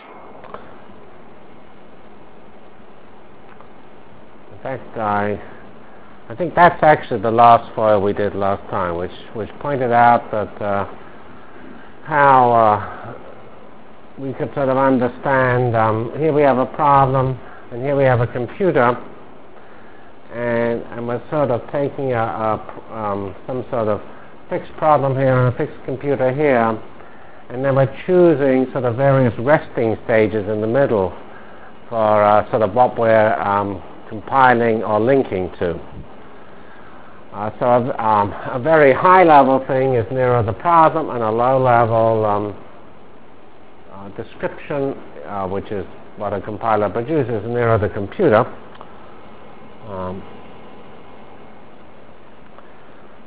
From CPS615-Introduction to Virtual Programming Lab -- Problem Architecture Continued and Start of Real HPF Delivered Lectures of CPS615 Basic Simulation Track for Computational Science -- 26 September 96. by Geoffrey C. Fox